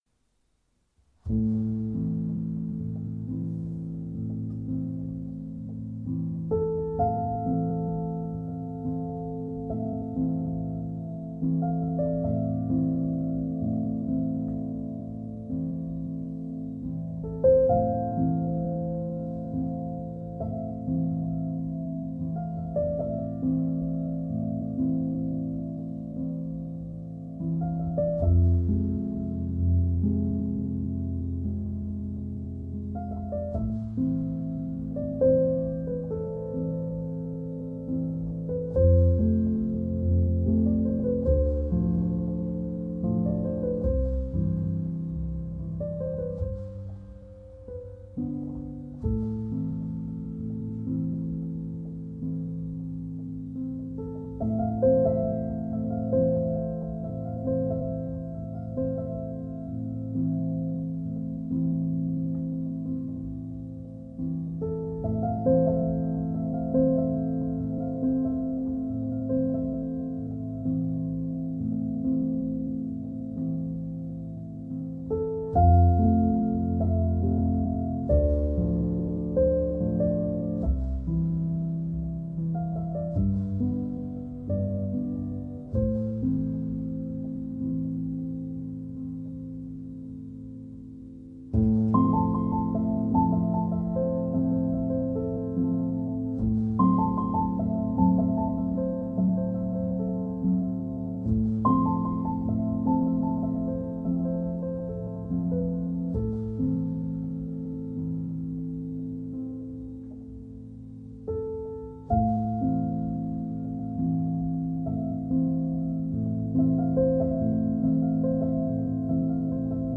آهنگ پیانو